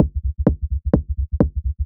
• muffled washed techno kick loop.wav
muffled_washed_techno_kick_loop_82p.wav